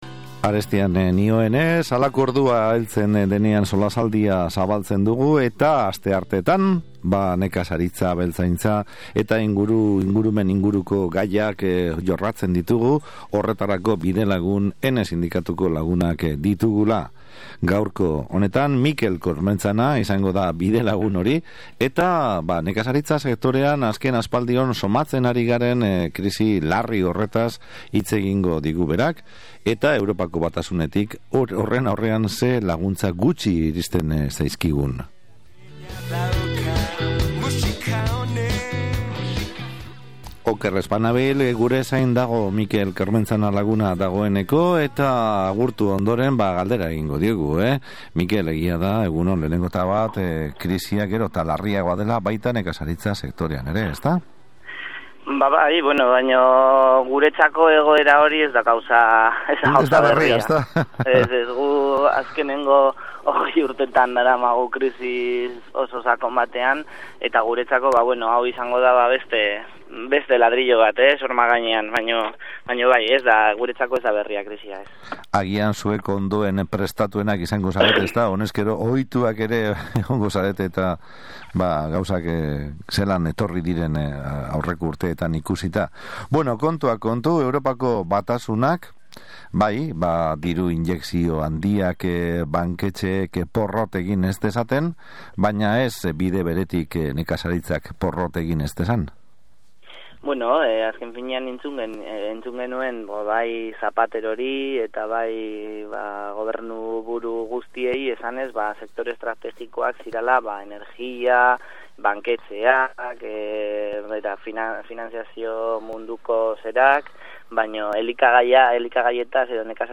Asteartero bezala, EHNE sindikatuko kideekin bat egin dugu Bilbo Hiria irratiaren Ibaizabal magazinaren solasaldian.
solasaldia